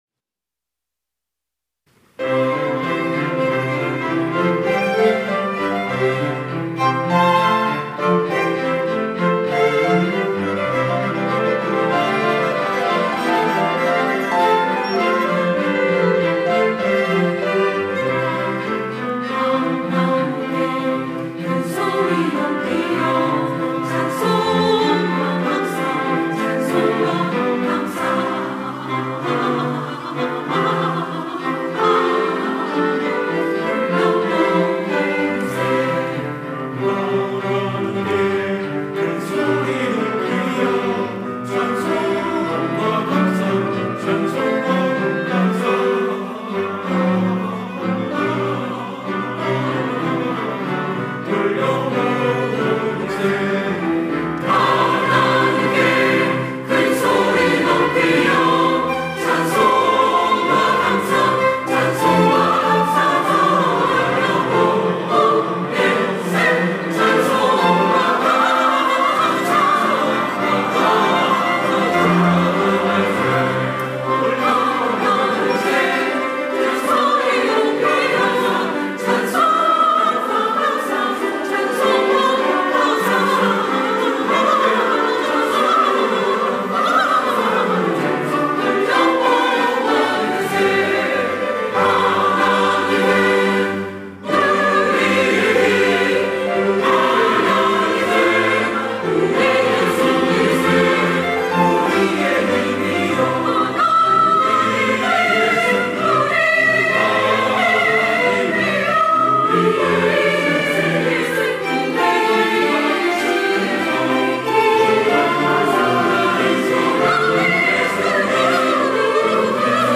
호산나(주일3부) - 하나님께 찬송드리세
찬양대